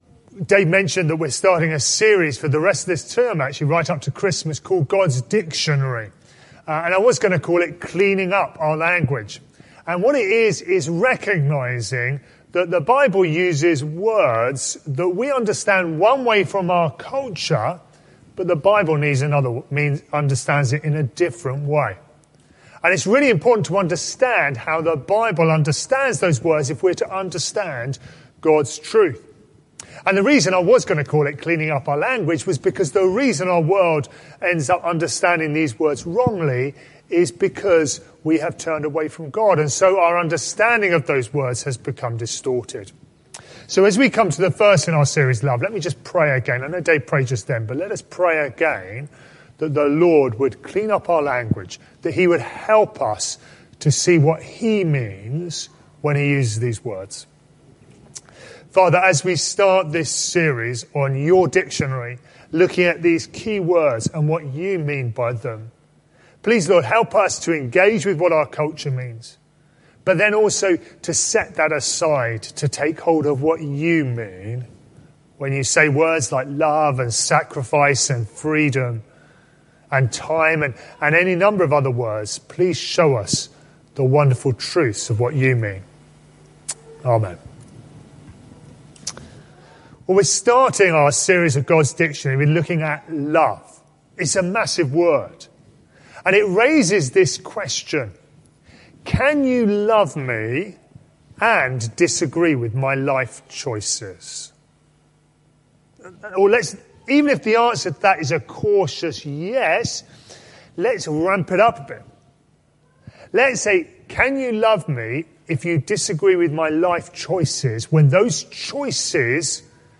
This sermon is part of a series: